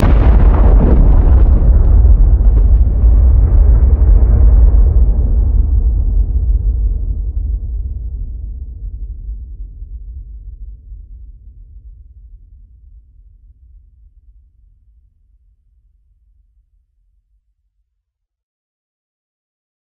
ExplosionMassive2.ogg